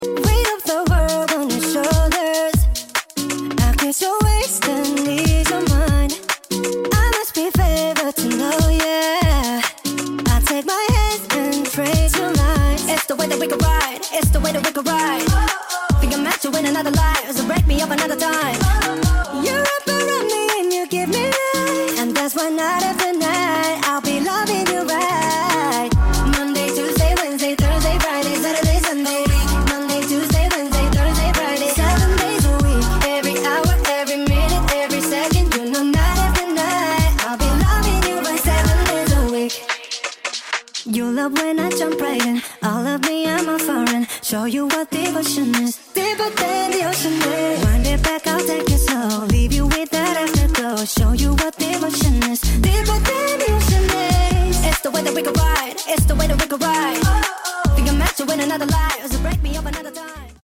(speed up)